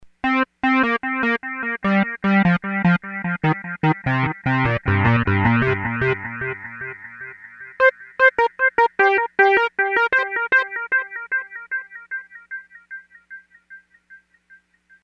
AnalogDelay
(Evolutionaries: B121)   Stereo 'analog' delay. Tweak the filter frequency to alter the decay tail.
AnalogDelay.mp3